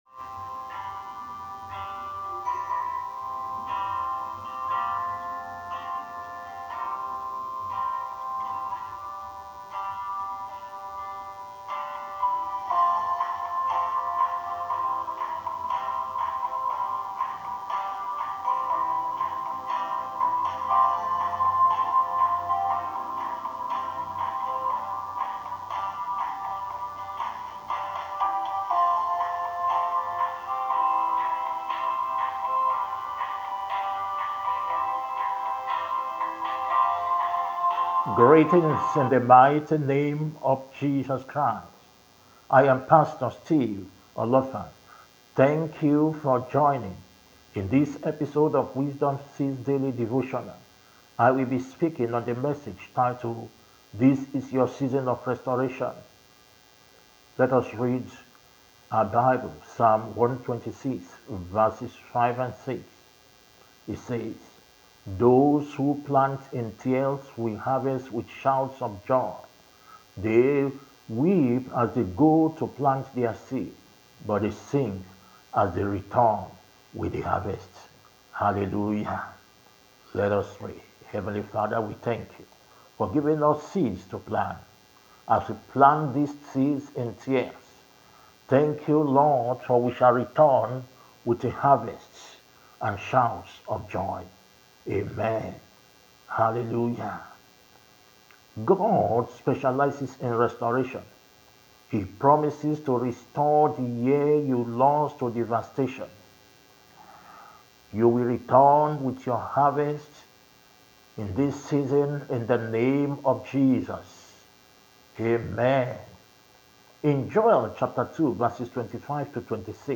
Daily Devotional